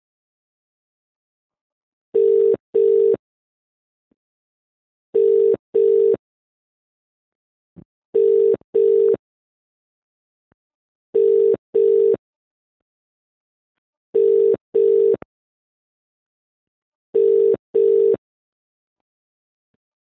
Free British Essex calling sound sound effects free download